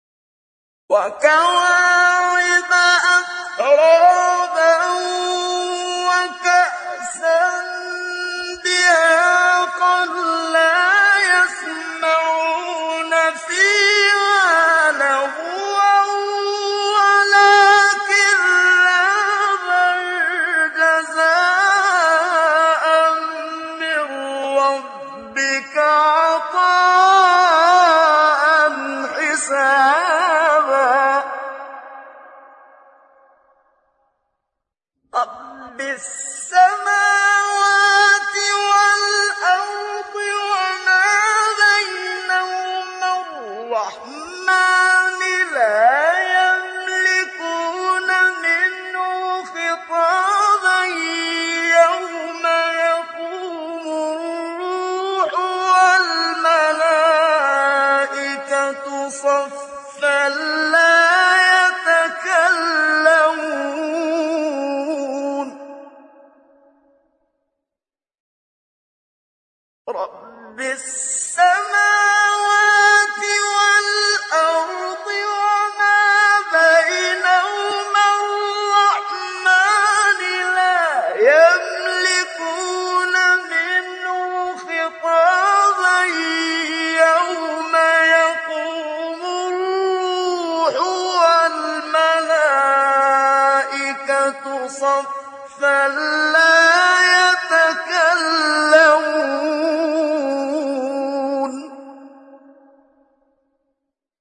آیه 33-38 سوره نبأ محمد صدیق منشاوی | نغمات قرآن | دانلود تلاوت قرآن